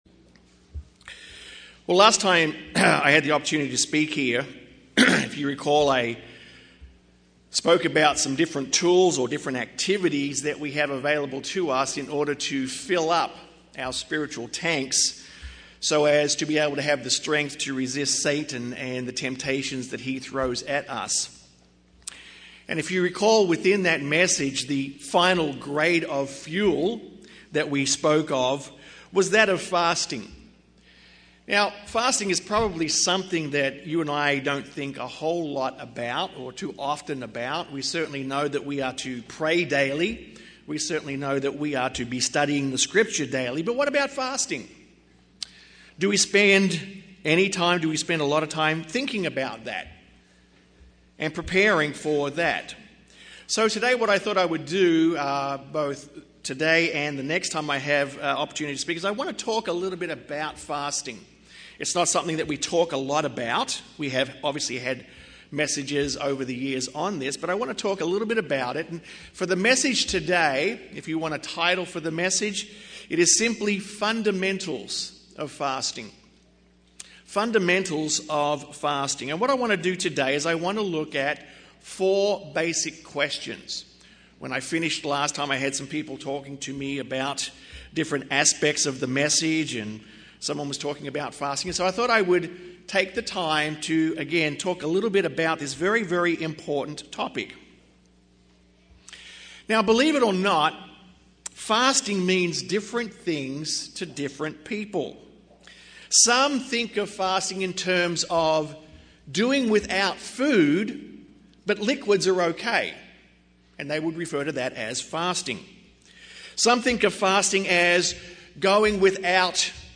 Fasting is one of the great tools for spiritual growth. This sermon covers 4 Fundamental questions regarding Fasting as shown in the scripture.